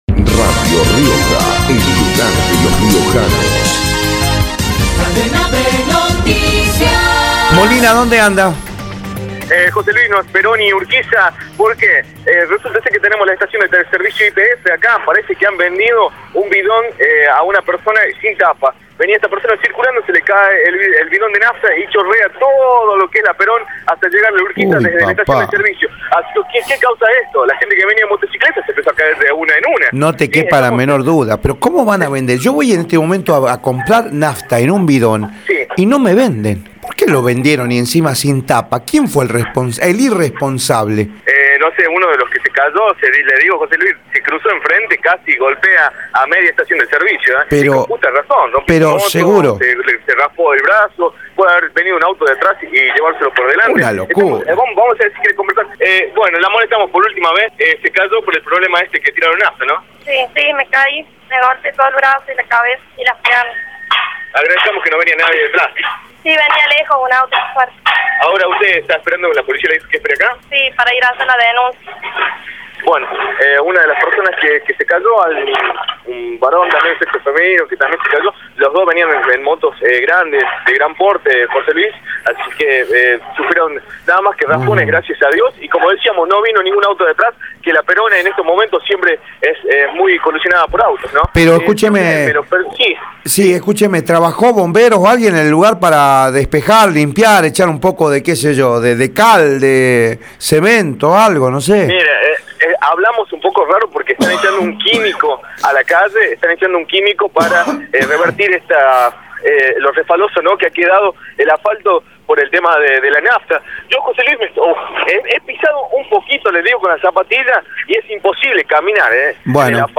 Informe sobre derrame de nafta en avenida Perón por Radio Rioja
informe-sobre-derrame-de-nafta-en-avenida-perc3b3n-por-radio-rioja.mp3